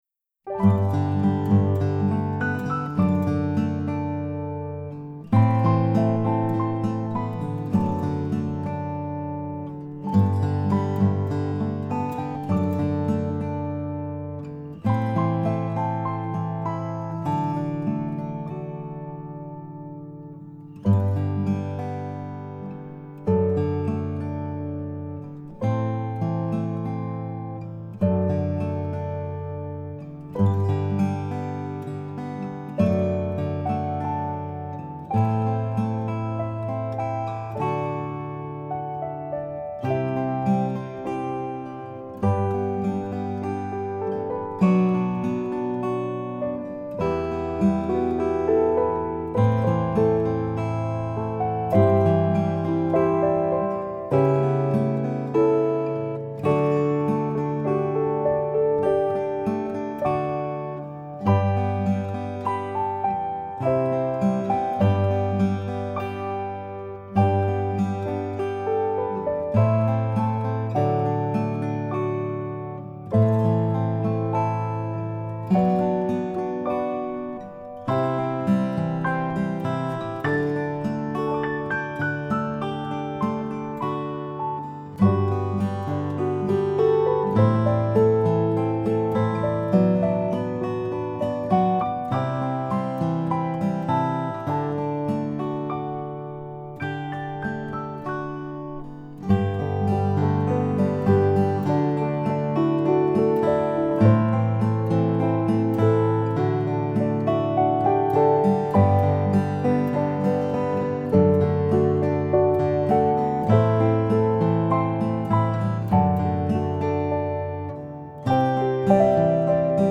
Below is both a vocal and guitar instrumental for it:
no-words-piano-guitar-7-4-20-n.mp3